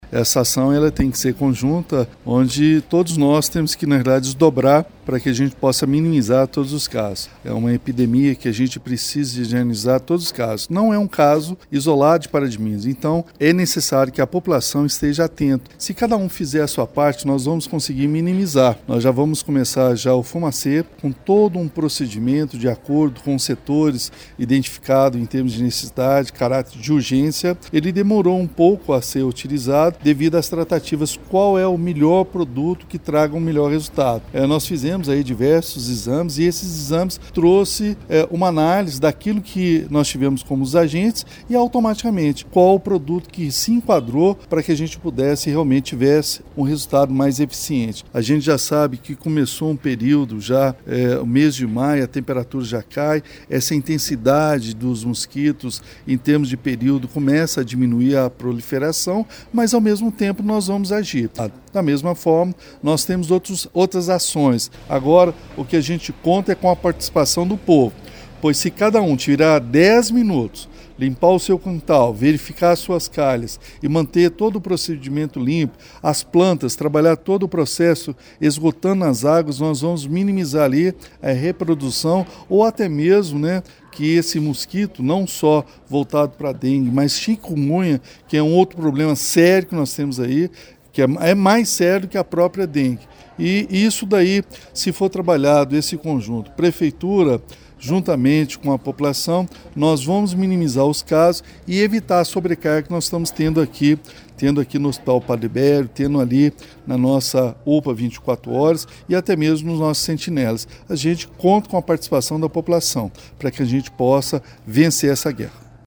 O prefeito Elias Diniz (PSD) salienta a importância da participação dos cidadãos paraminenses nesta luta contra o Aedes aegypti para acabar com a epidemia de Dengue: